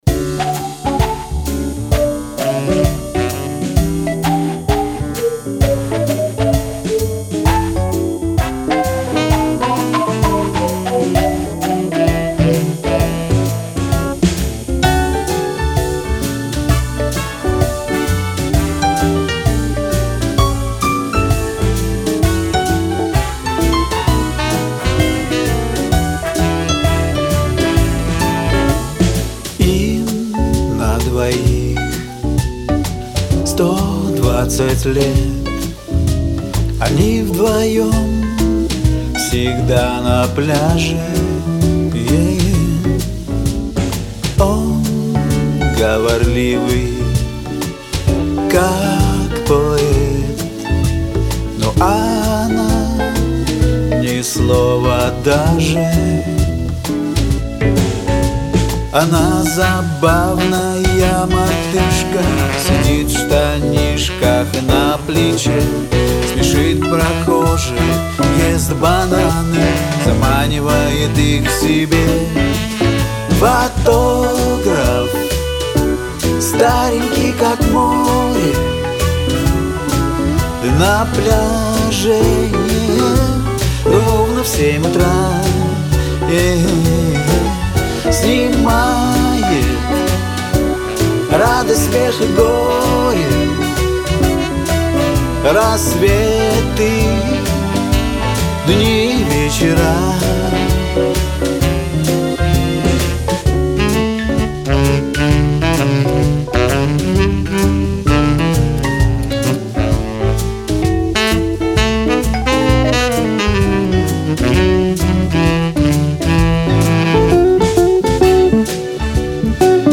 Фотограф jazz